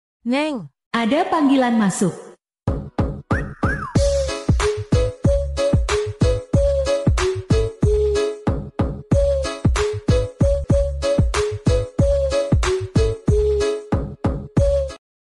Genre: Nada dering panggilan